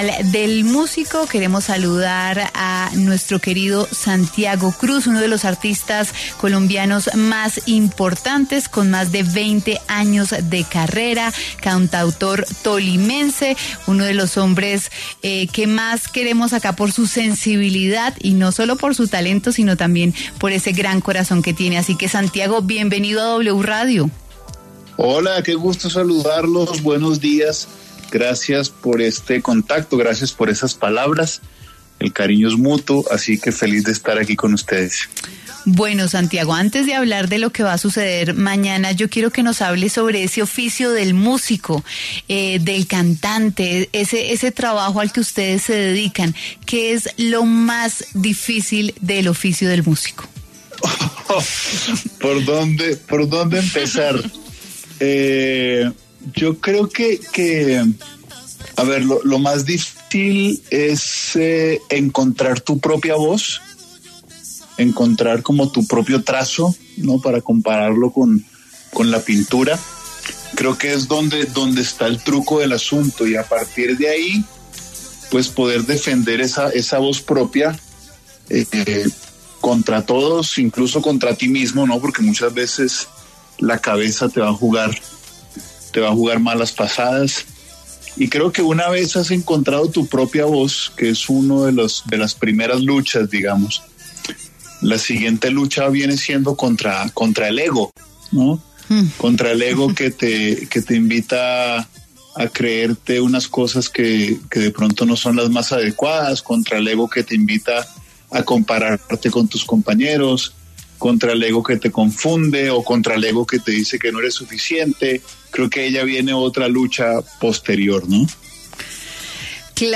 El reconocido cantautor tolimense Santiago Cruz estuvo en los micrófonos de W Fin de Semana, dónde compartió su visión sobre la satisfacción que trae su carrera.